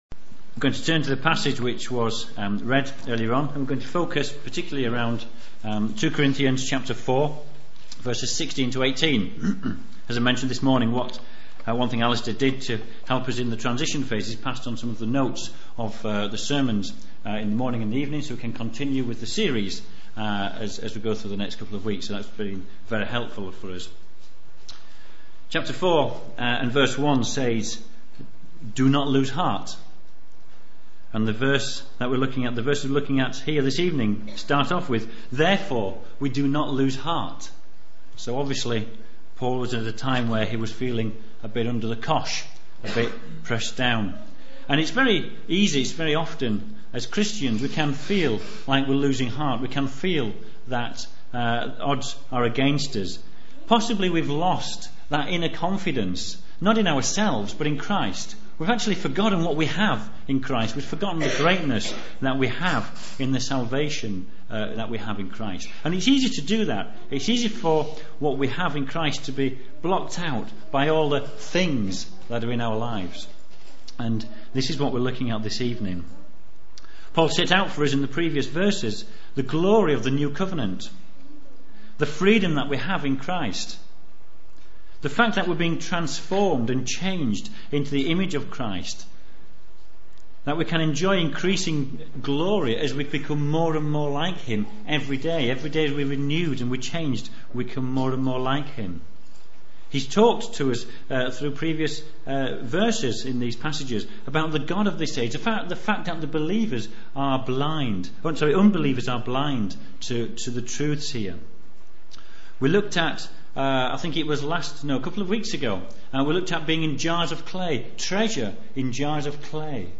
Latest Episode 2 Corinthians 4:16-18 Download the latest episode Note: in some browsers you may have to wait for the whole file to download before autoplay will launch. A series of sermons on 2 Corinthians